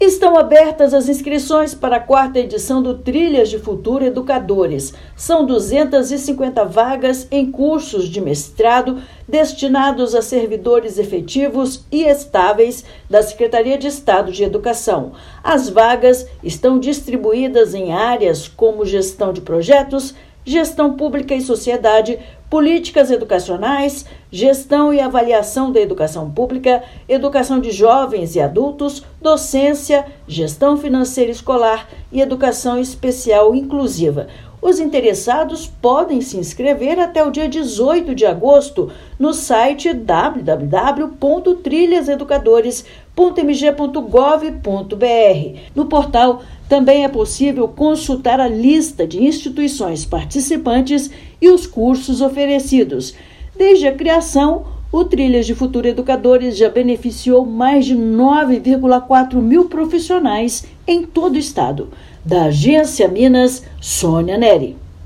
Oportunidade oferece cursos de mestrado gratuitos para servidores da Educação de Minas Gerais, que podem se inscrever até o dia 18/8. Ouça matéria de rádio.